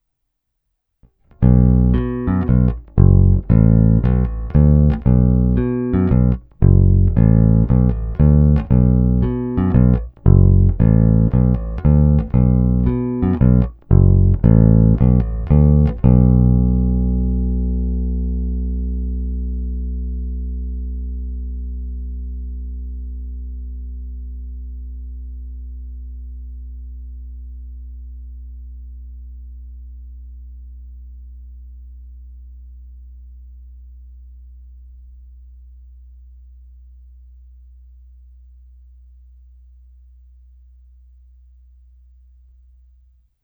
Analýzou frekvenčního spektra jsem došel k poznání, že má výraznější nižší středy v pásmu 300 až 600 Hz.
Nahrál jsem jen jednu ukázku na porovnání s původním snímačem, a to rovnou do zvukovky.
Ukázka EMG GZR snímač